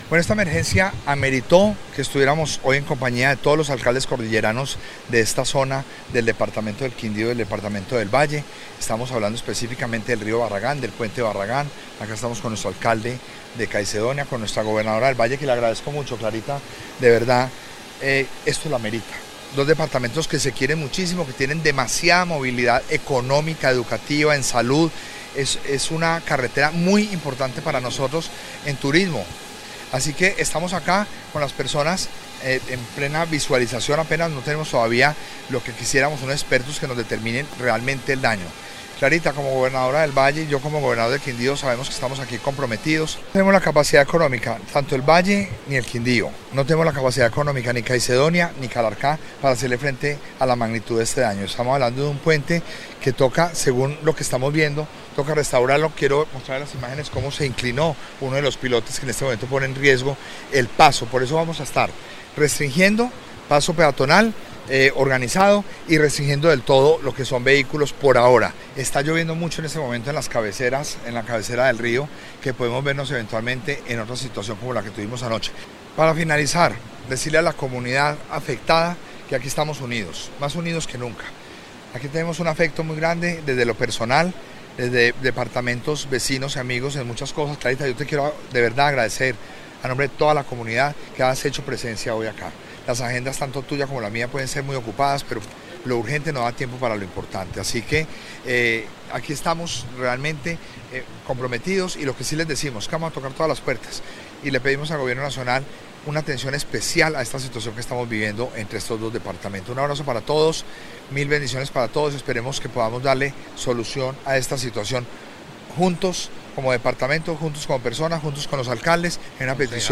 ESCUCHE: Gobernador del Quindío Roberto Jairo Jaramillo
Desde la orilla del río, haciendo la primera inspección con ingenieros y organismos de atención de emergencias, Roberto Jairo Jaramillo Cárdenas, Gobernador del Quindío, y Clara Luz Roldán González, gobernadora del Valle del Cauca, acompañados de los alcaldes cordilleranos y líderes de la comunidad afectada, instaron al gobierno nacional para que aporte recursos que permitan atender la emergencia que incomunicó al departamento del Quindío con el Valle del Cauca.
Roberto-Jairo-Jaramillo-Cárdenas-Gobernador-del-Quindío-Barragán.mp3